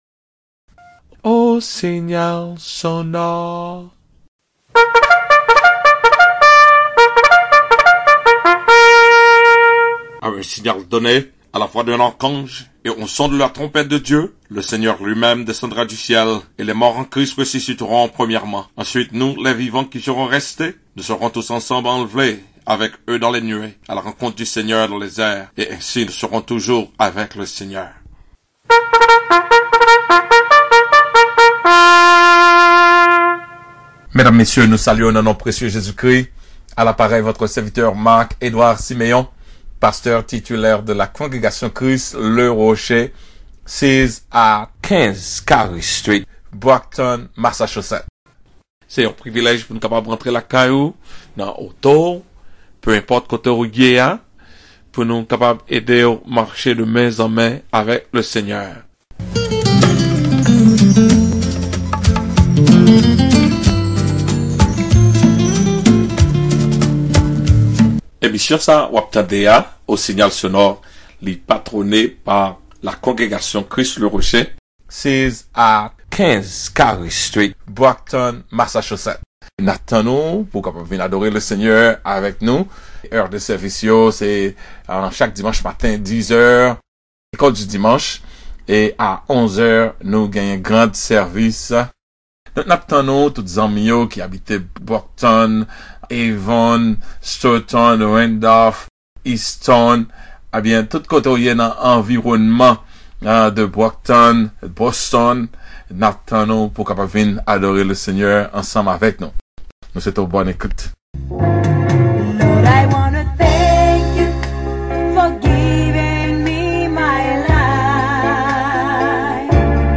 THERE IS NO GOD LIKE JEHOVAH (SERMON)